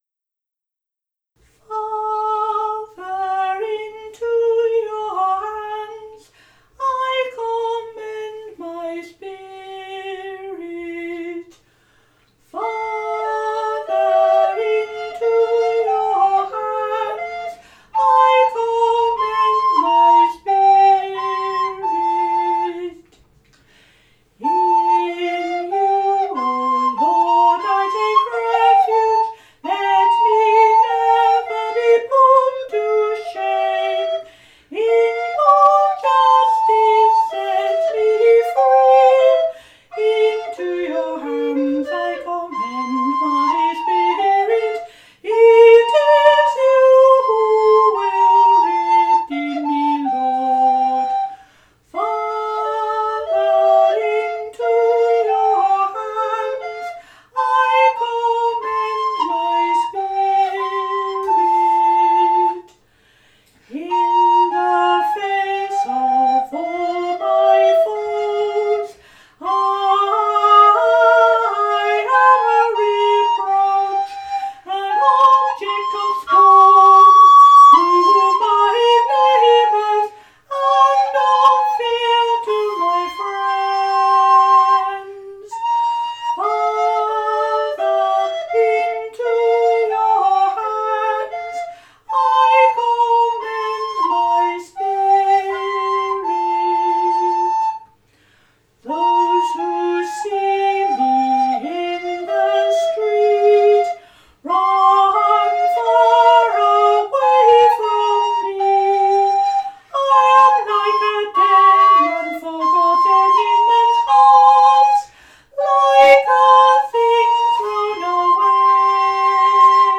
playing the recorder